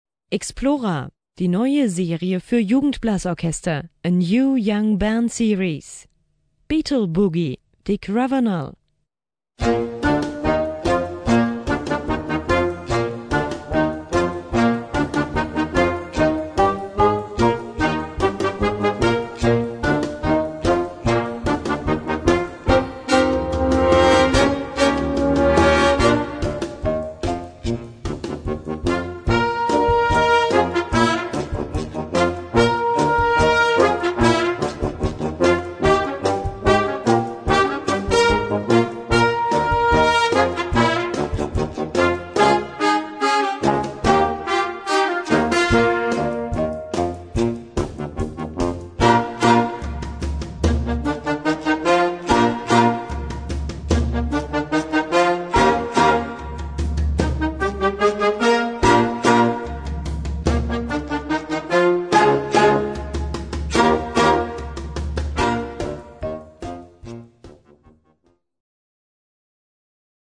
Gattung: Boogie für Jugend-Blasorchester
Besetzung: Blasorchester